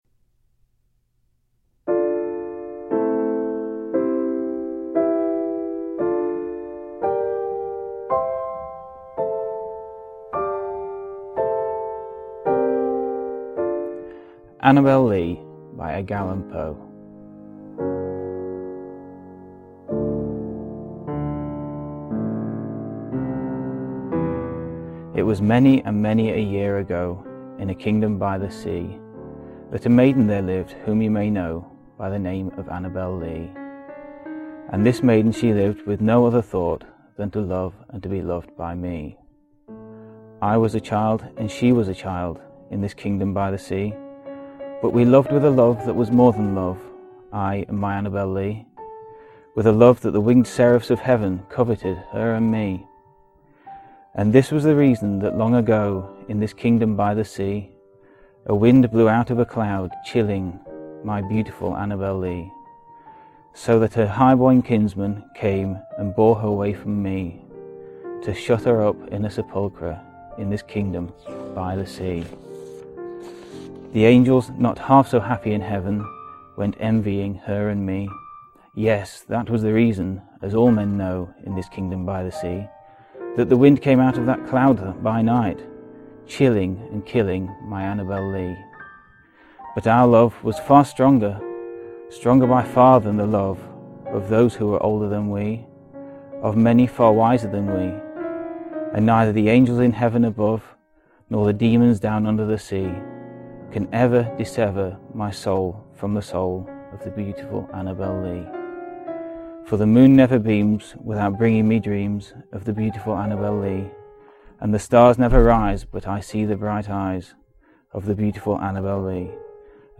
La música que sentireu és del romàntic Debussy, qui es va inspirar en els relats de Poe en algunes de les seues obres.